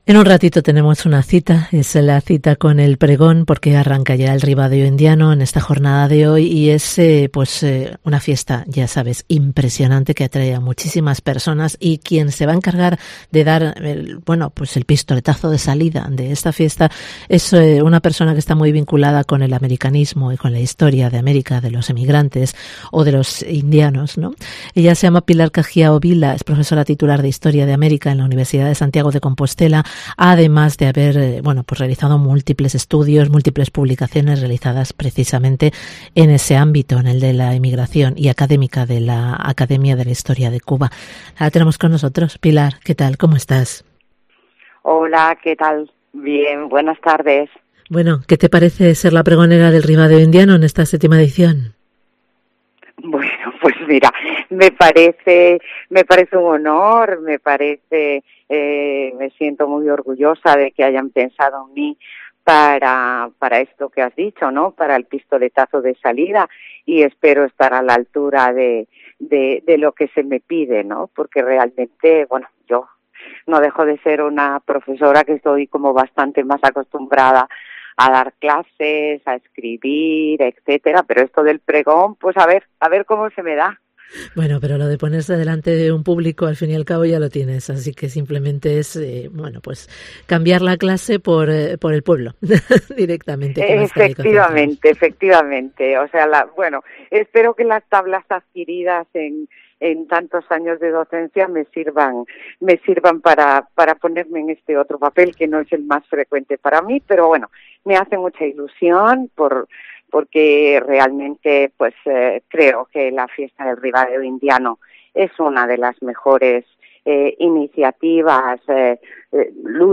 arranca con su pregón el Ribadeo Indiano